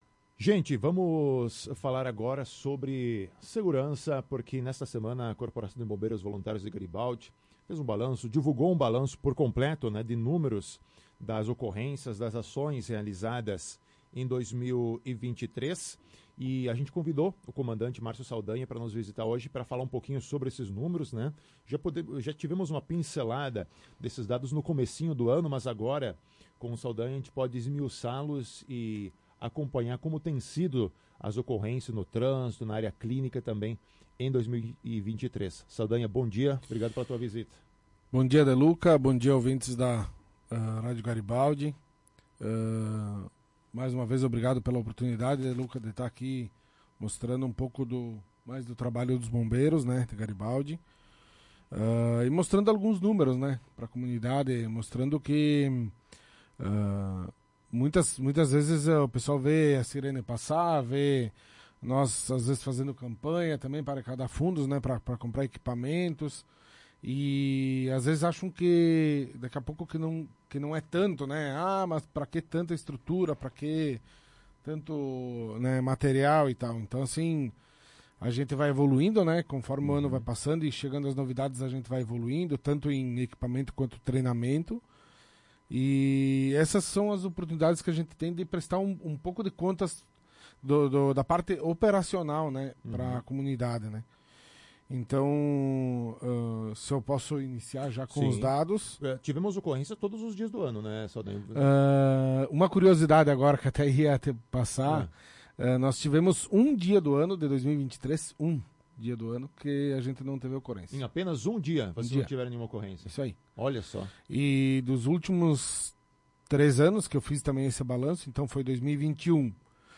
(entrevista completa em ouça a notícia)